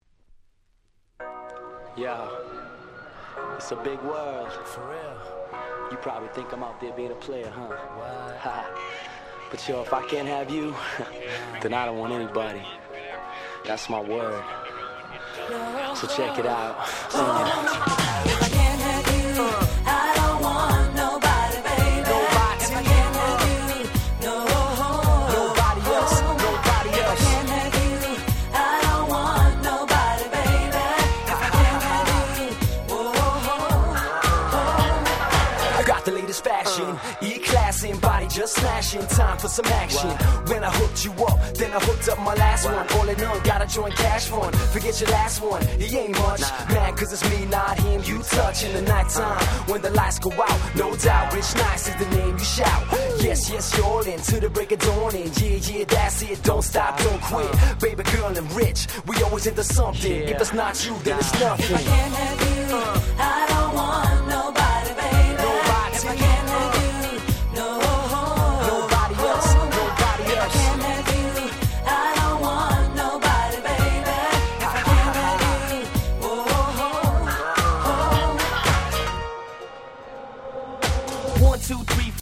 99' Nice Dance Pop / R&B !!
USの人気男性アイドルグループによるSmash Hit !!